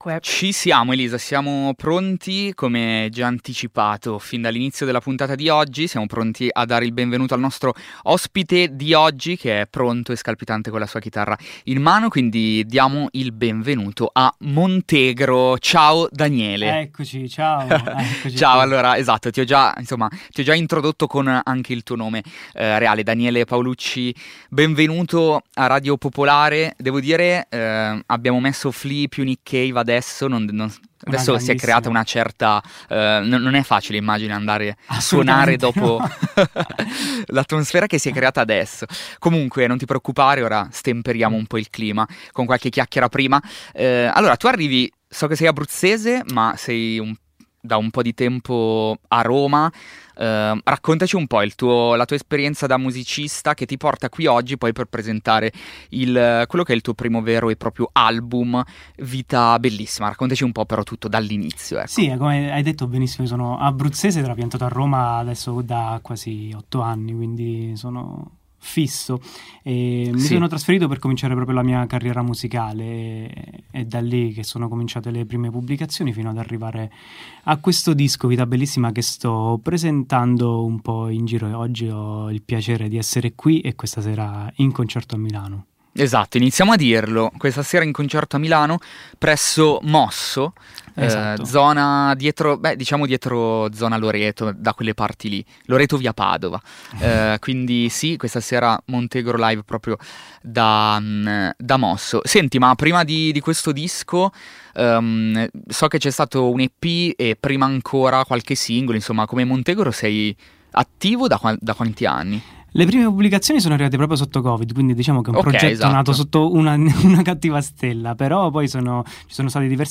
Ascolta l’intervista e il MiniLive